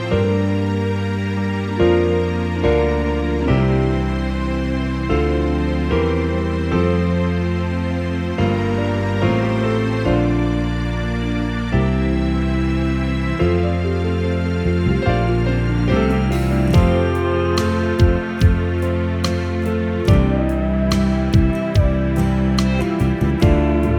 No Backing Vocals Crooners 3:59 Buy £1.50